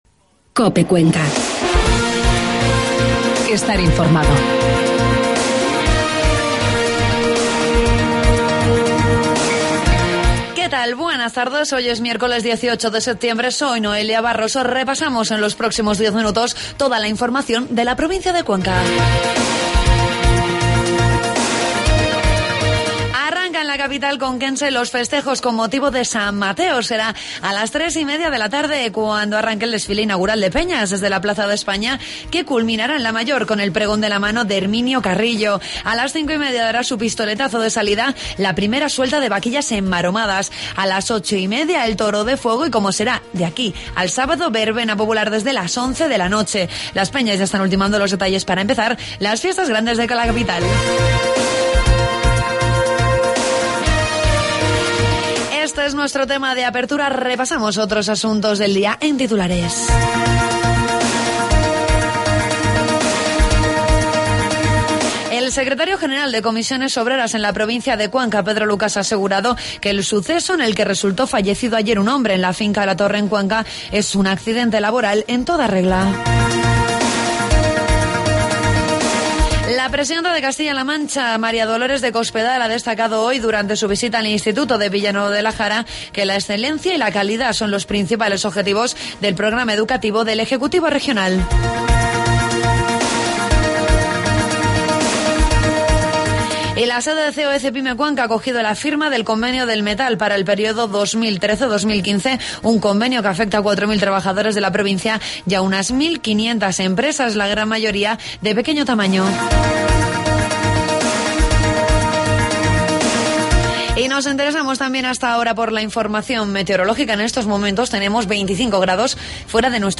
AUDIO: Toda la información de la provincia de Cuenca en los informativos de mediodía de COPE